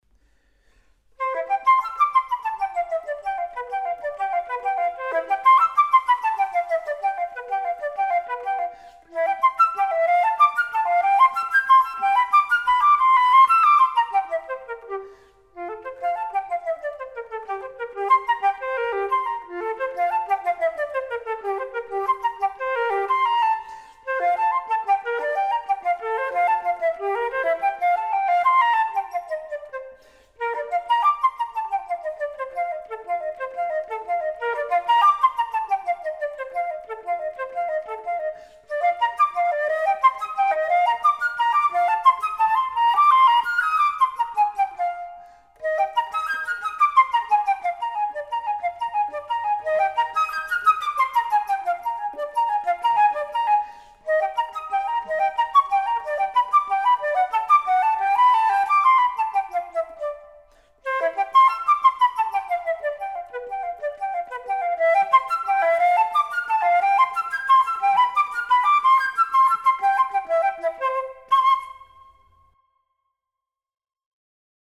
in tutti i toni maggiori e minori e relative Scale
flauto